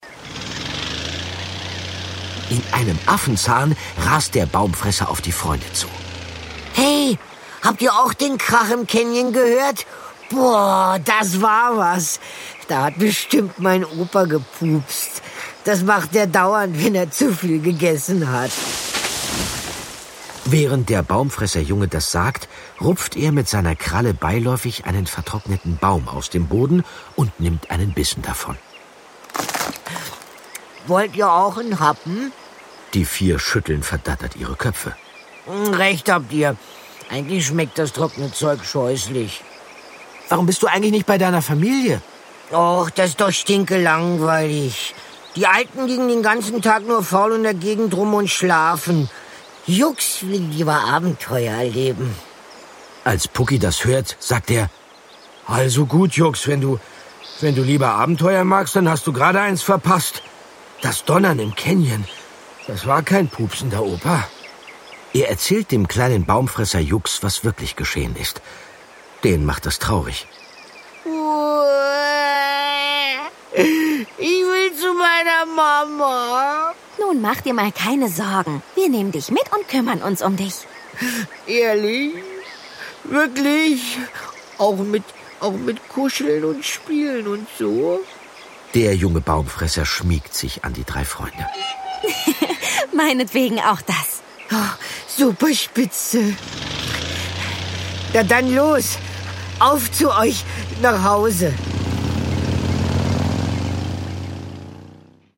Andreas Fröhlich von "Die drei ???" ist einer der bekanntesten und beliebtesten Sprecher.
Schlagworte Abenteuer • Auto • Baumfresser • Bo • Dinos • Dinosaurier • Dinosaurier; Kinder-/Jugendliteratur • Freundschaft • Hörbuch; Lesung für Kinder/Jugendliche • Hörspiel • Neo Pangea • Pukki • Spannung • Spaß • Tanka • Urwald • Urzeit